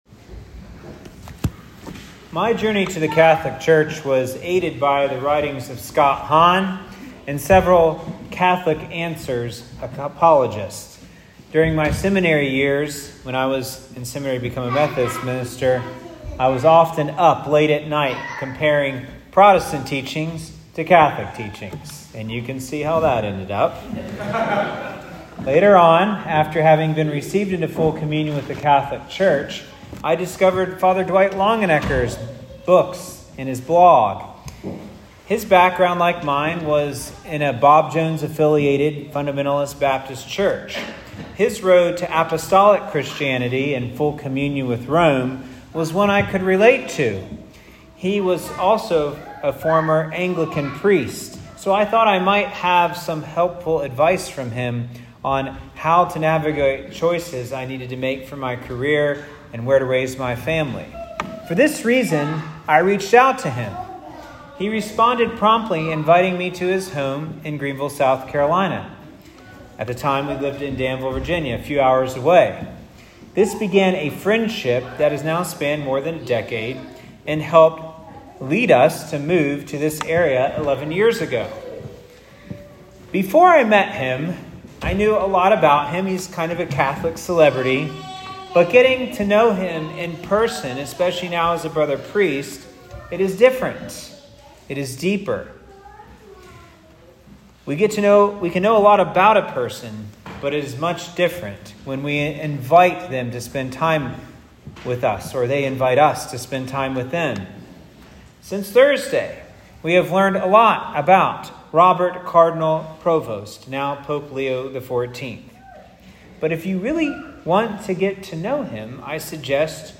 Listen to Father's Homily Below How you can better hear the Great Shepherd through the shepherds of our church: Easter IV - Good Shepherd Sunday.m4a Habemus Papam!